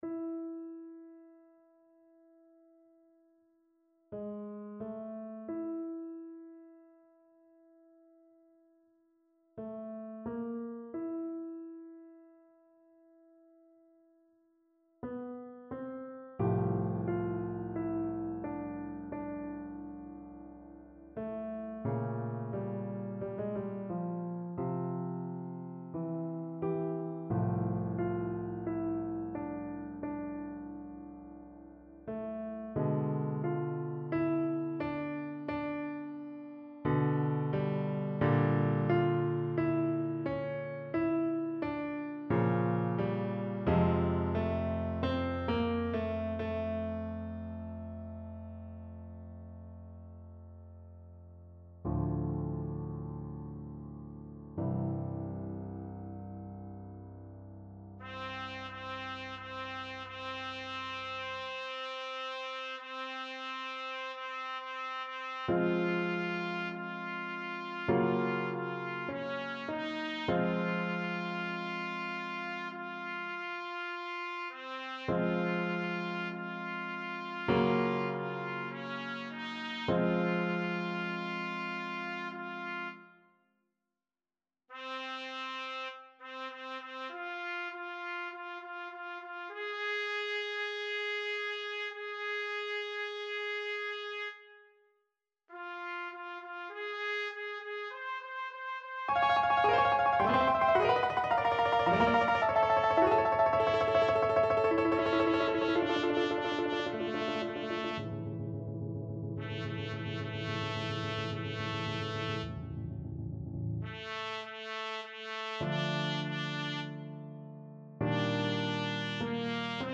Trumpet version
4/4 (View more 4/4 Music)
Lento =44
Trumpet  (View more Intermediate Trumpet Music)
Classical (View more Classical Trumpet Music)